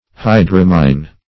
Search Result for " hydramine" : The Collaborative International Dictionary of English v.0.48: Hydramine \Hy*dram"ine\, n. [Hydroxyl + amine.]